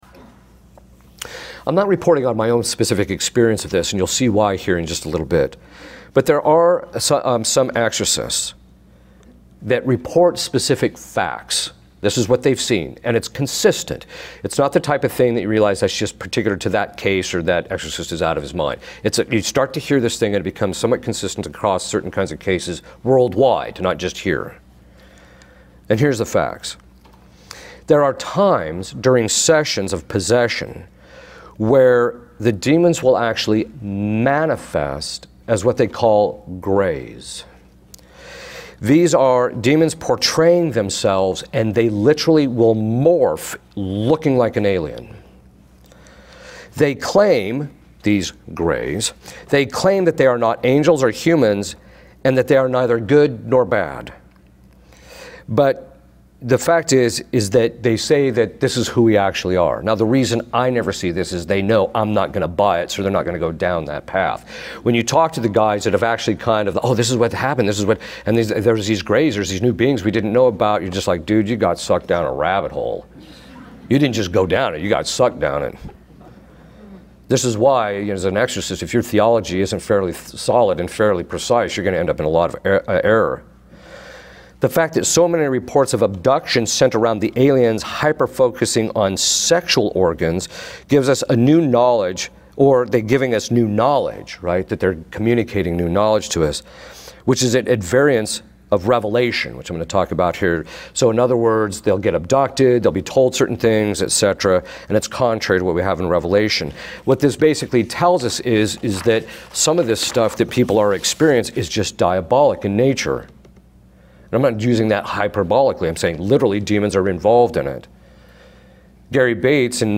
Padre Exorcista Católico Habla Sobre sound effects free download
Padre Exorcista Católico Habla Sobre Los 'Extraterrestres'.